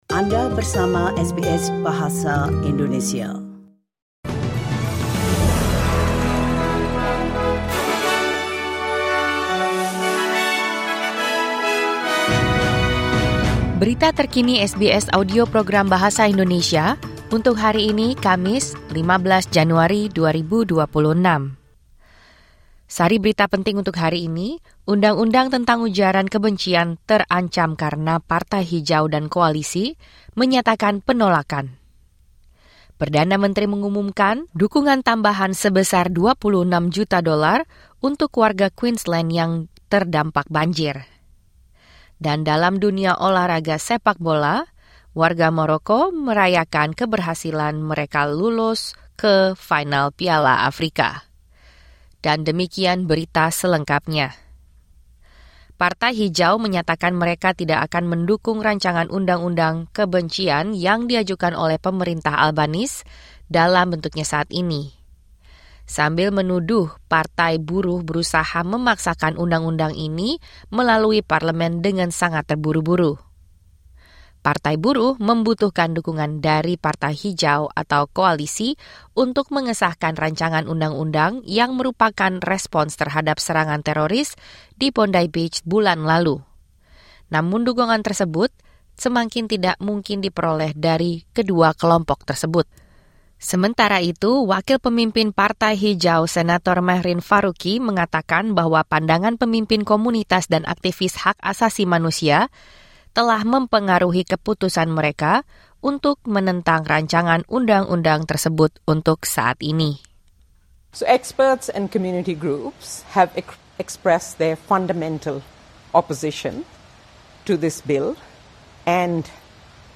Berita Terkini SBS Audio Program Bahasa Indonesia - Kamis 15 Januari 2026